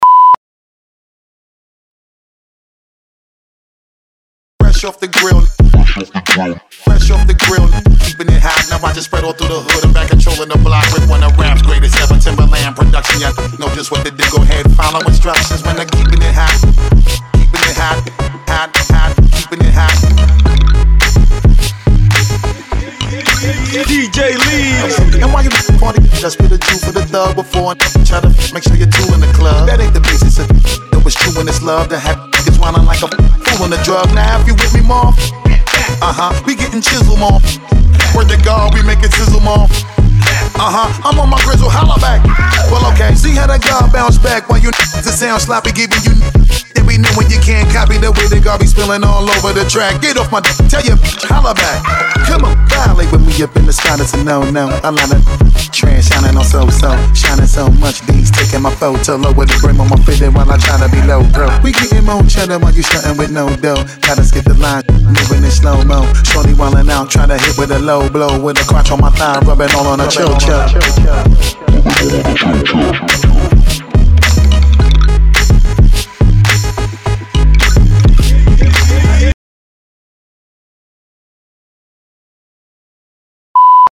*bleep: Are you on your spot?
*3 seconds silence: to look better on screen to be sure that you are not walking anymore
*few seconds silence: stay in your place till you hear the next bleep
Solo Music: